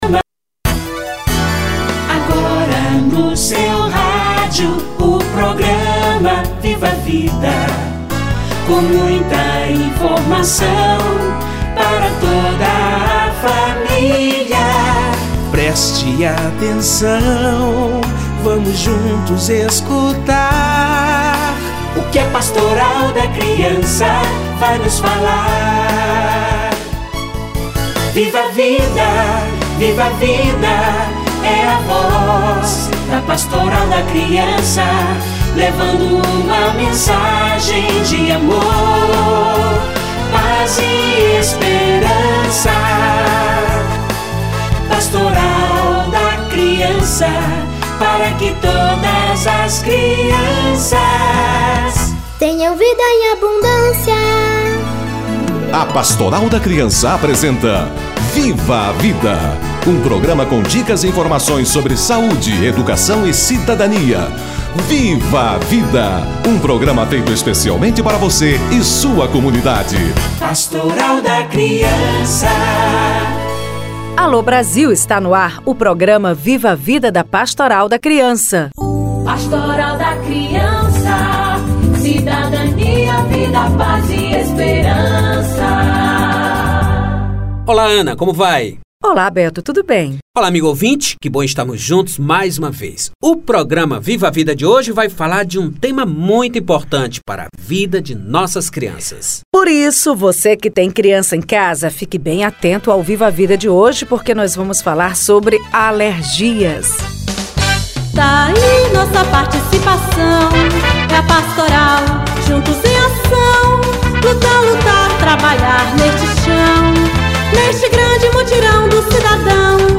Alergia nas crianças - Entrevista